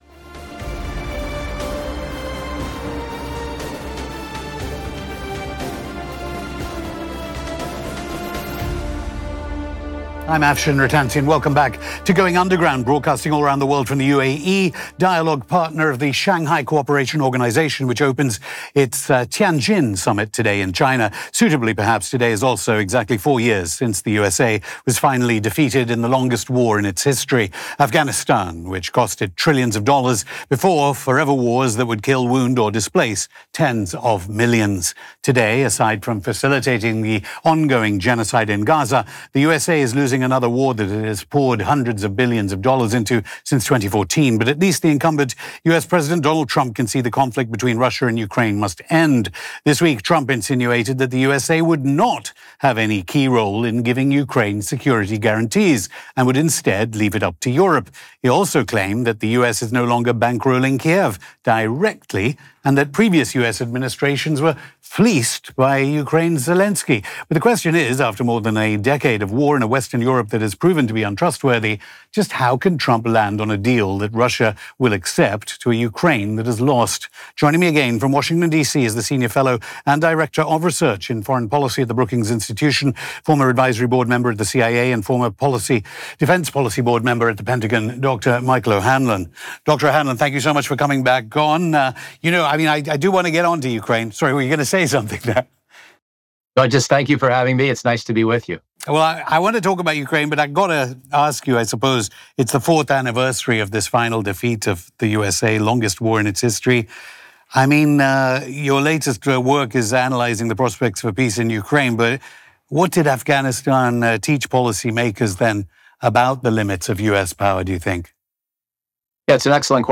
Ex-CIA Advisor & Afshin Rattansi Have HEATED Exchange Over Ukraine Proxy War